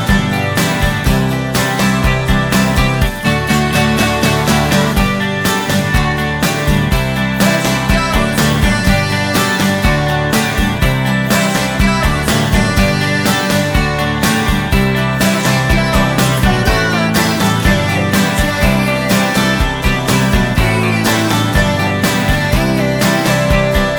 Minus Lead Guitar Indie / Alternative 2:41 Buy £1.50